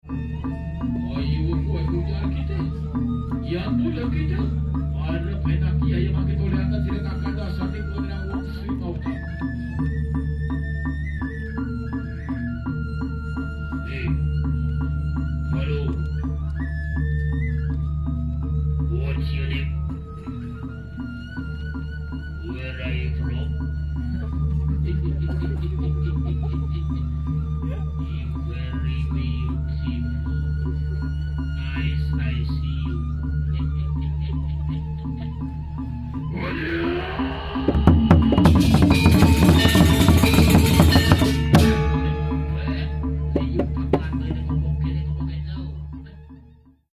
Alle Sounds gibt es in 2 Formaten, im RealAudio-Format in ausreichender Qualität und im MP3-Format in sehr guter Qualität.
8 Barong-Dance 1
legong5.mp3